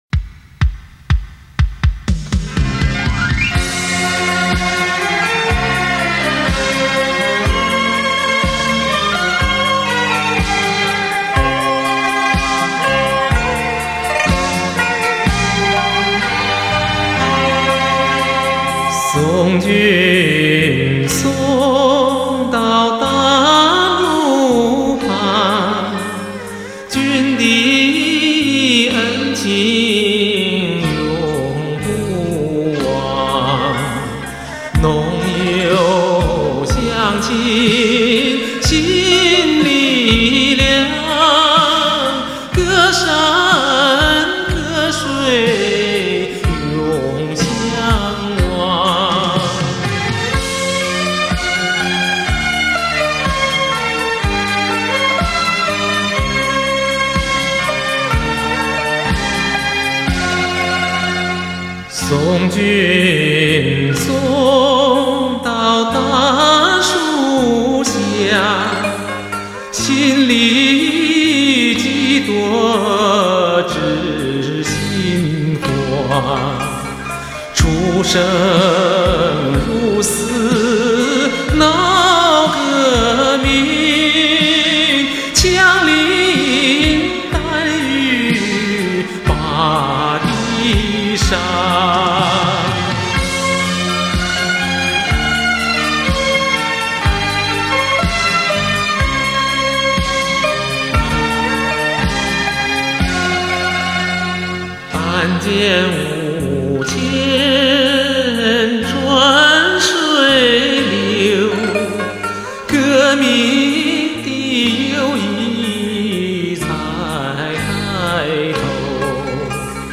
音质：正版CD转320K/MP3